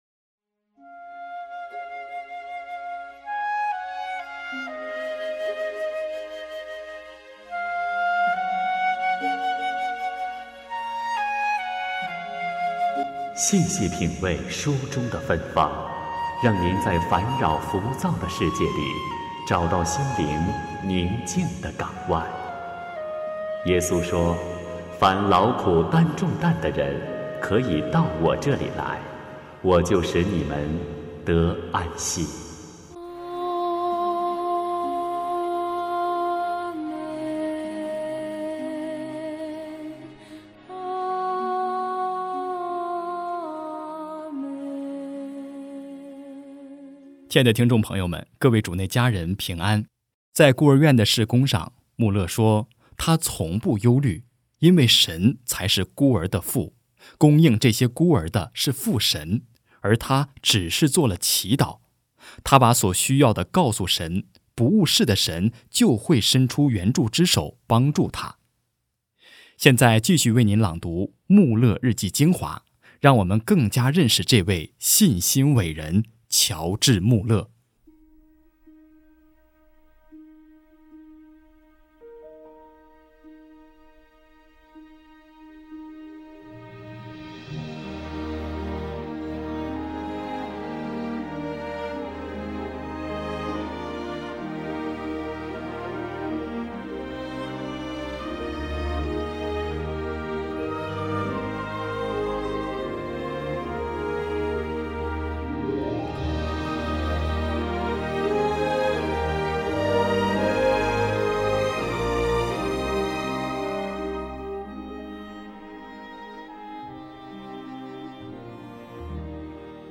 现在继续为您朗读 《慕勒日记精华》， 让我们继续认识这位信心伟人—-乔治·慕勒。